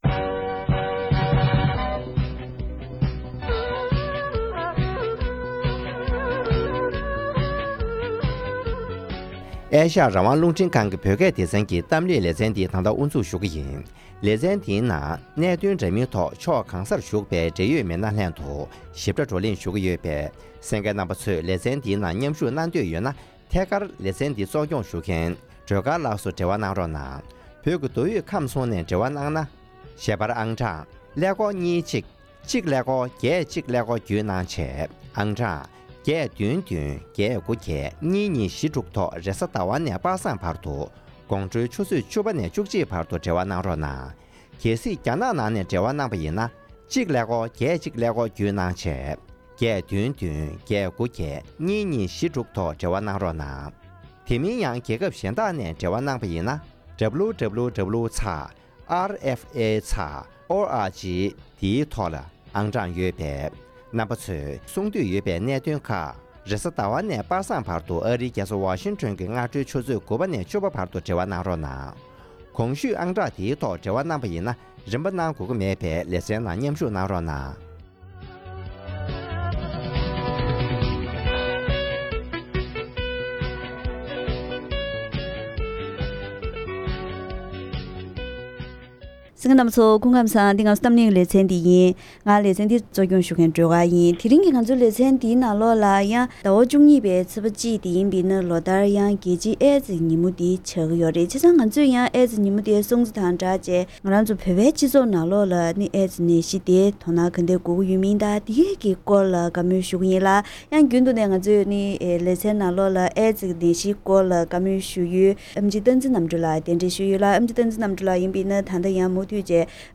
༄༅། །དེ་རིང་གི་གཏམ་གླེང་ལེ་ཚན་ནང་། འཛམ་གླིང་ཨེ་ཙེ་ཉིན་མོ་དང་སྟབས་བསྟུན་གྱིས་ཨེ་ཙེ་ནད་གཞི་འདི་བོད་ནང་ཁྱབ་གདལ་ཇི་ཡོད་དང་ནད་གཞི་འདིས་བོད་མི་རིགས་ལ་ཉེན་ཁའི་རང་བཞིན། དེ་བཞིན་ནད་གཞི་འདིའི་སྔོན་འགོག་དང་སློབ་གསོའི་སྐོར་ལ་འབྲེལ་ཡོད་སྨན་པ་དང་ ལྷན་བཀའ་མོལ་ཞུས་པ་ཞིག་གསན་རོགས་གནང་།།